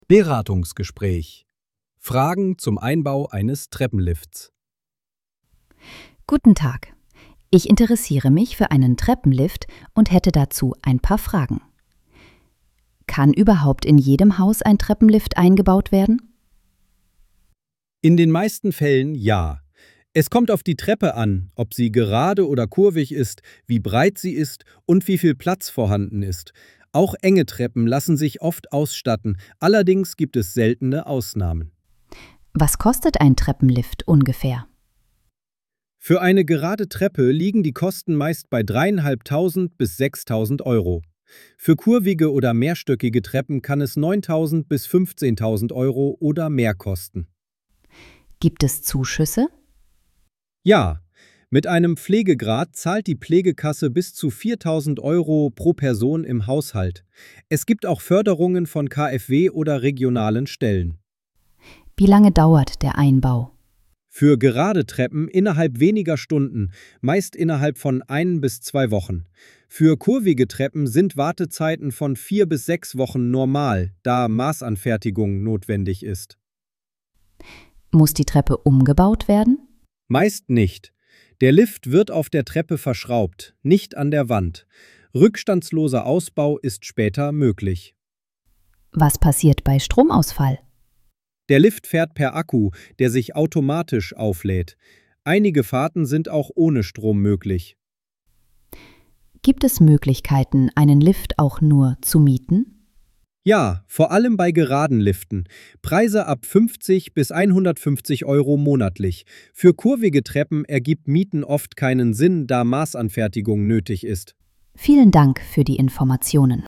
Ein erstes Informationsgespräch
Wir haben Ihnen einen kleinen Ausschnitt eines Beratungsgesprächs zusammengestellt, wie es beginnen könnte.
erstinfo-gespraech-Treppenlift.mp3